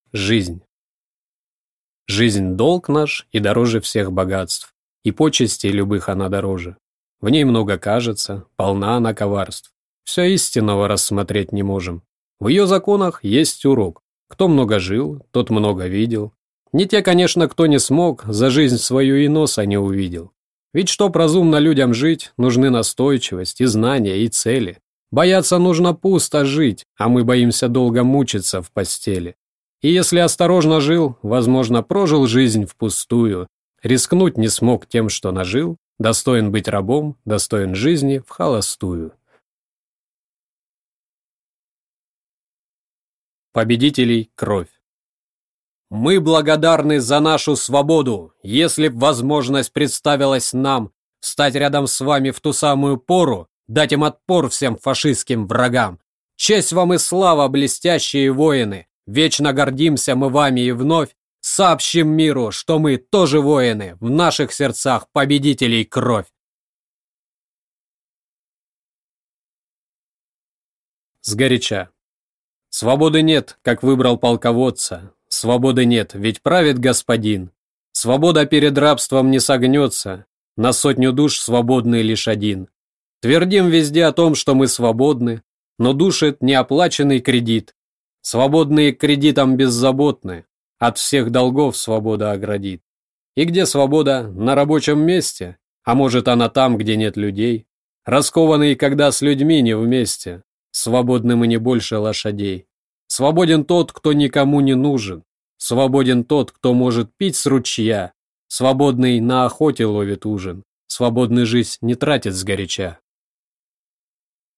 Аудиокнига Не поддавайтесь тьме внутри | Библиотека аудиокниг